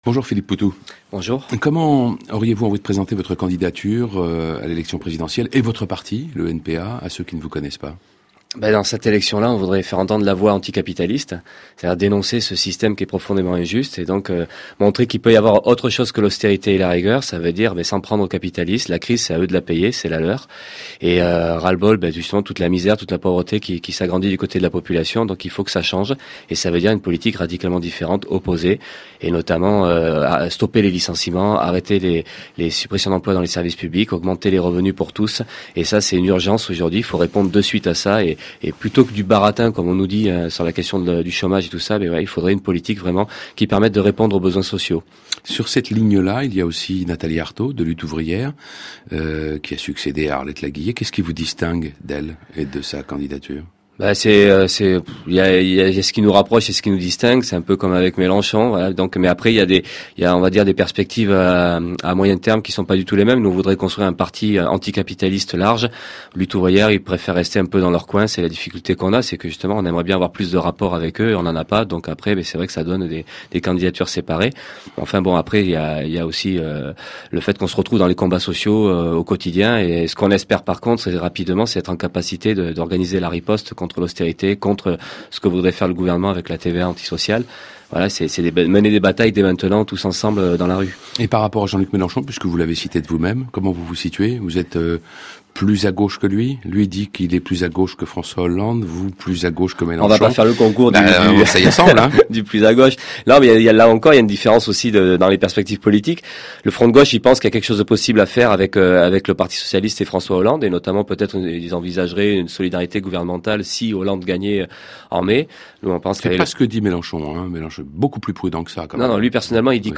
Philippe Poutou, candidat du Nouveau parti anti-capitaliste (NPA), était ce 7 février au matin l’invité de RFI.
Invité du matin — Philippe Poutou, candidat du Nouveau parti anticapitaliste (NPA) pour l’élection présidentielle 2012